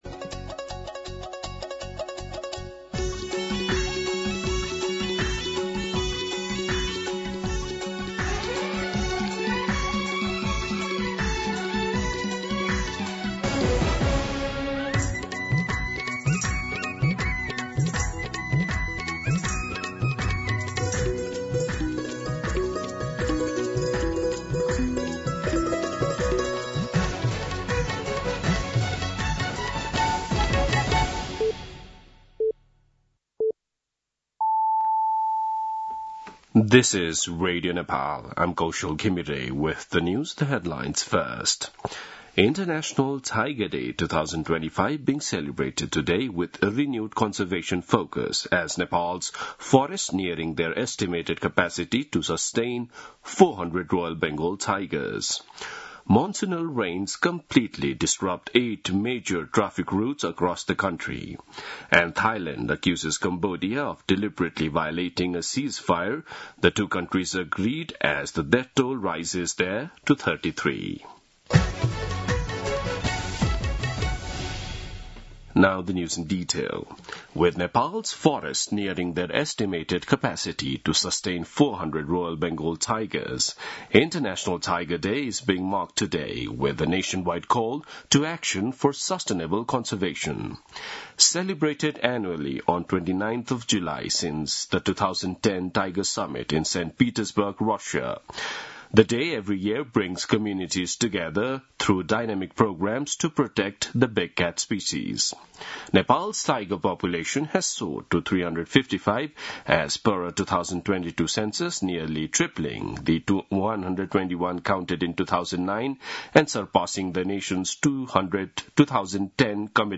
An online outlet of Nepal's national radio broadcaster
दिउँसो २ बजेको अङ्ग्रेजी समाचार : १३ साउन , २०८२
2pm-News-04-13.mp3